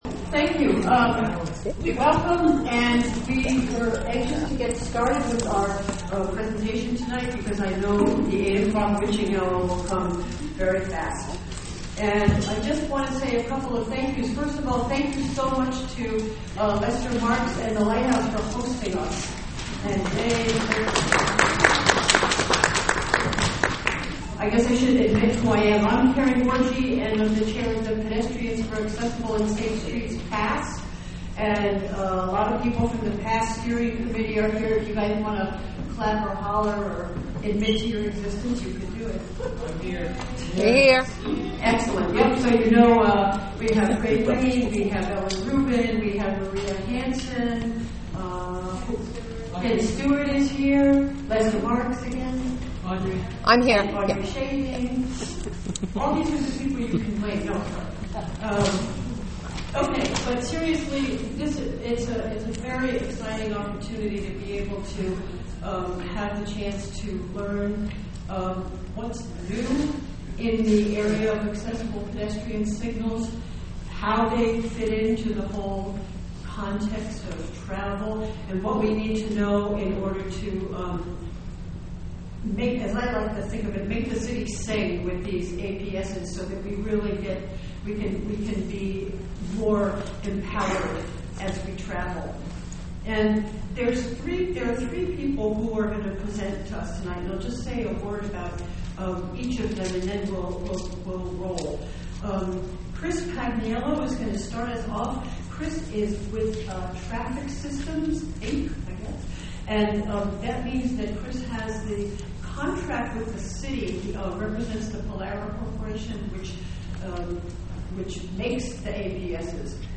PASS Town Hall Meeting
On November 12, 2013 the PASS Coalition sponsored a town hall meeting held at Lighthouse International.